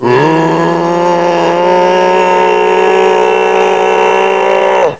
assets/ctr/nzportable/nzp/sounds/zombie/r6.wav at 628db8ede89cdcfce99e9c10e38628dd07e9164c